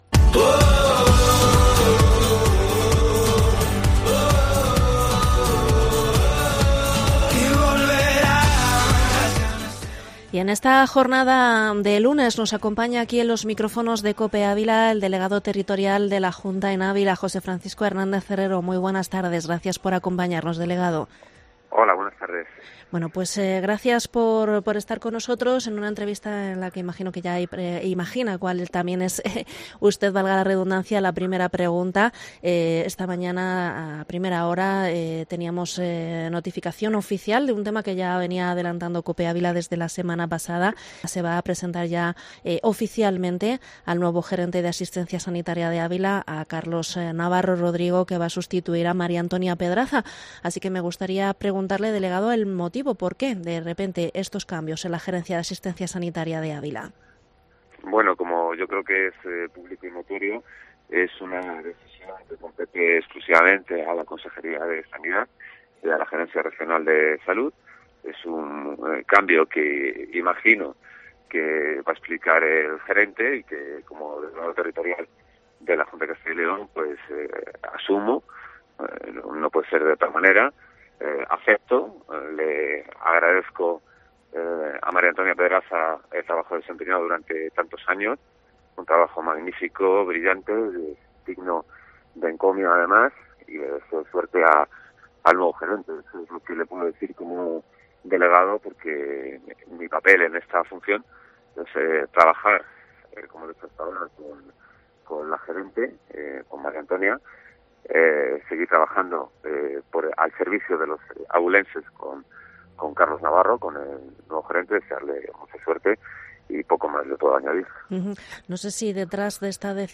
Entrevista delegado territorial de la Junta en Ávila 18 octubre